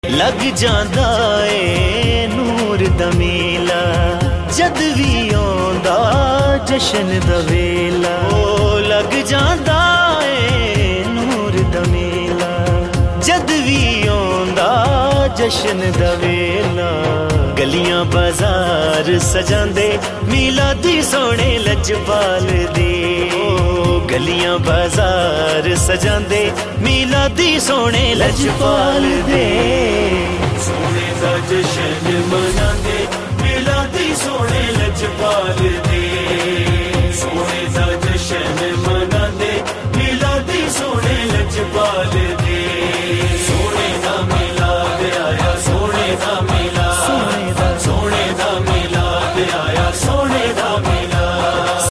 Naat Status